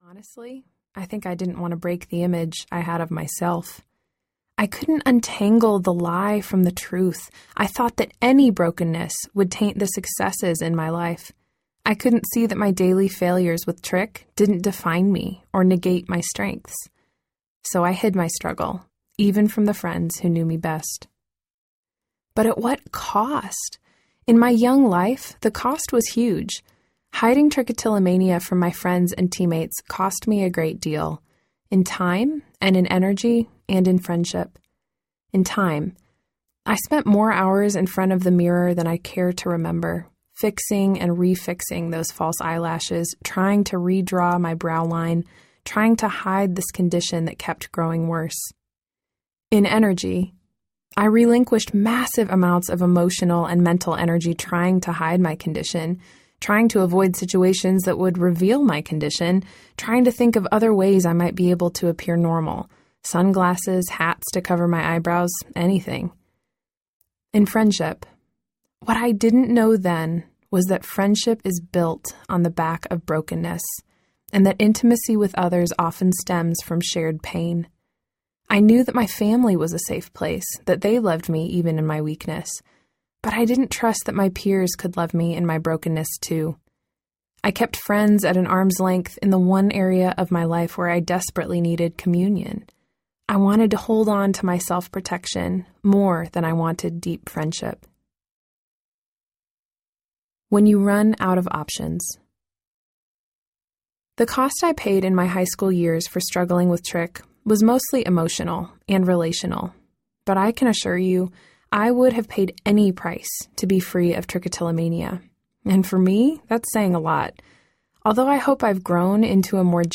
Still Waiting Audiobook
6.3 Hrs. – Unabridged